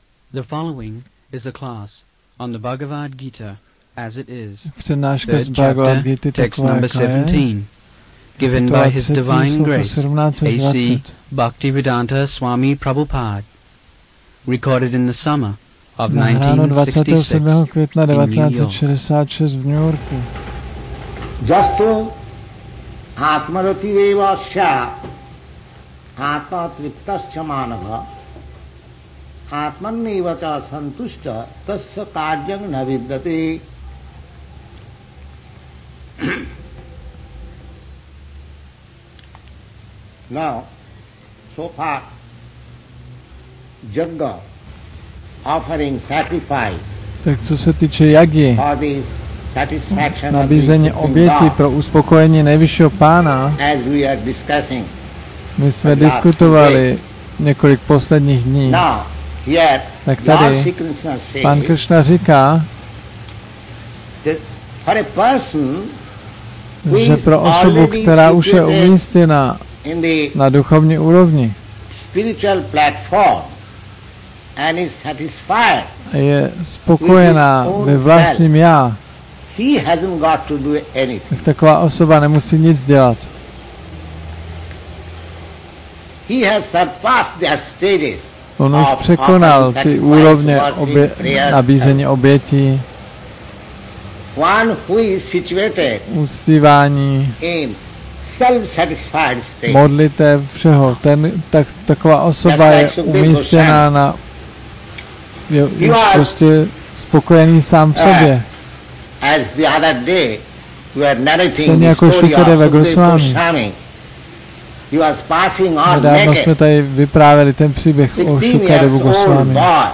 1966-05-27-ACPP Šríla Prabhupáda – Přednáška BG-3.17-20 New York